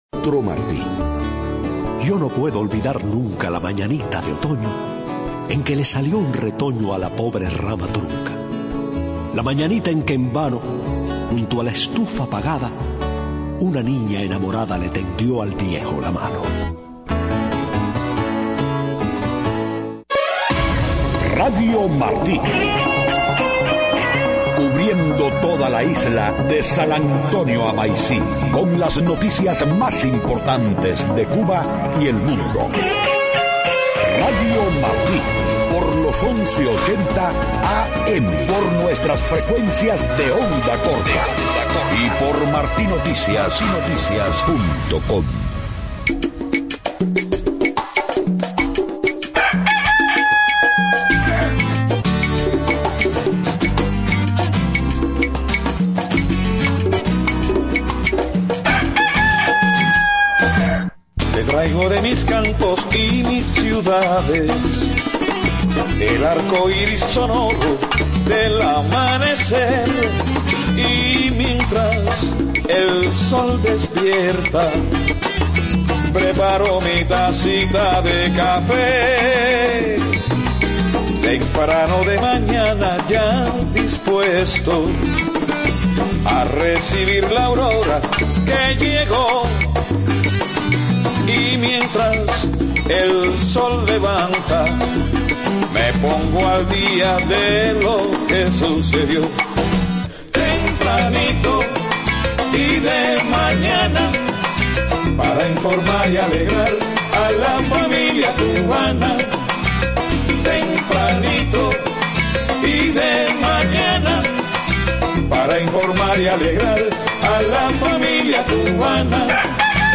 5:00 a.m. Noticias: Confirma el Vaticano que el papa Francisco visitará Cuba en septiembre.